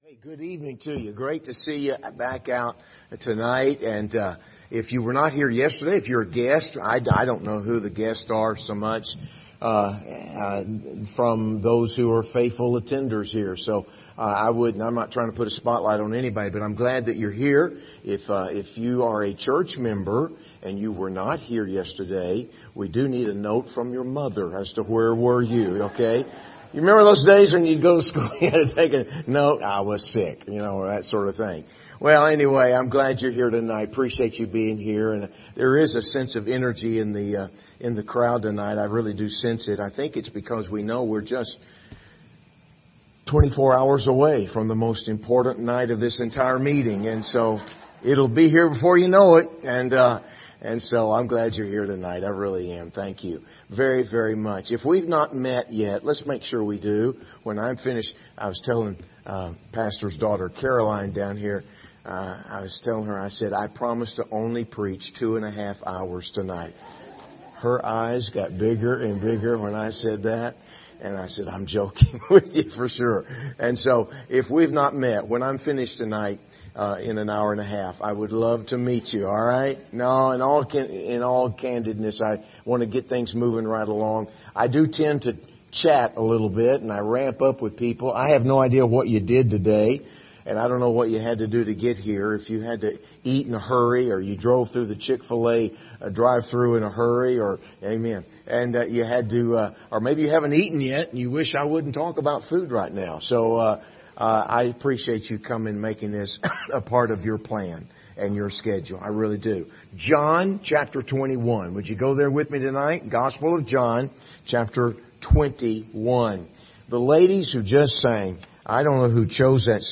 John 21:1-22:17 Service Type: Revival Service View the video on Facebook « Casting All Your Cares Have You Seen the Holy Spirit?